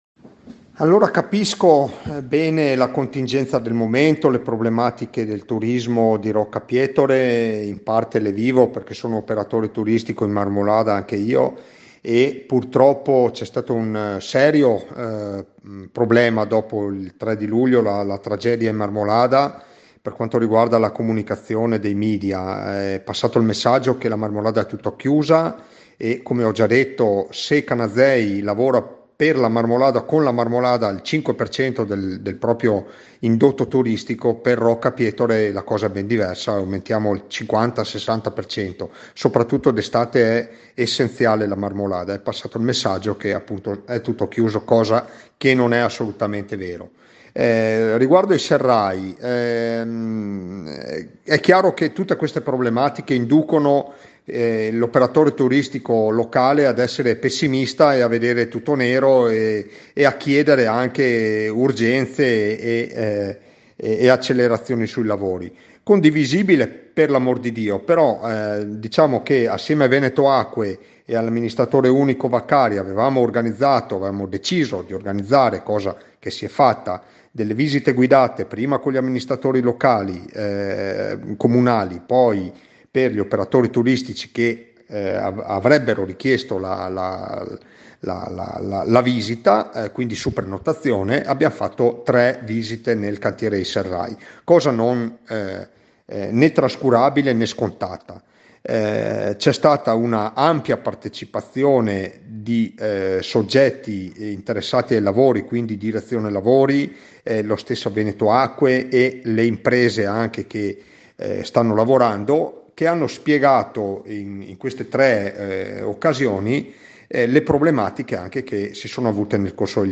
In quell’occasione erano state spiegate le problematiche legate alla ricostruzione e alla messa in sicurezza dell’area, gli intoppi intervenuti negli anni e la prospettiva futura. Per questo il sindaco Andrea De Bernardin prende la parola e ritorna sull’argomento.